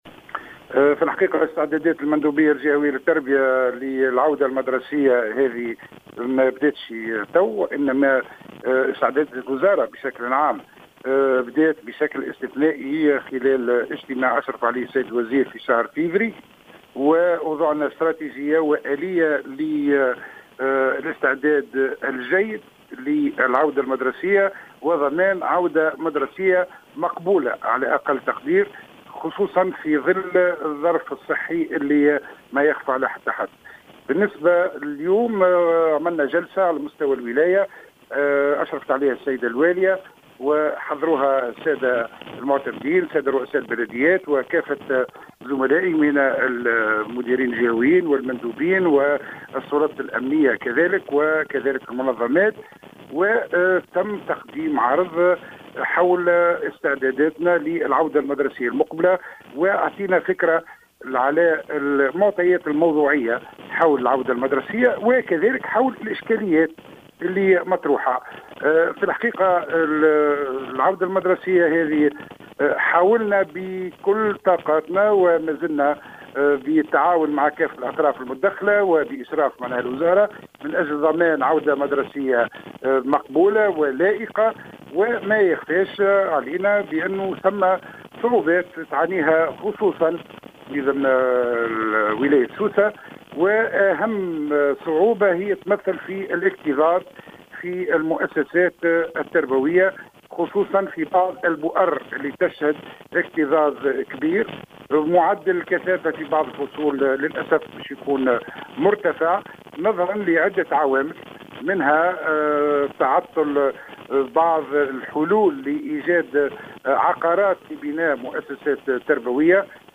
وفي تصريح للجوهرة أف أم، أكدّ المندوب الجهوي للتربية بسوسة نجيب الزبيدي أنّ الاكتظاظ في الأقسام يعد من أبرز النقائص المسجلة خلال السنة الدراسية المقبلة.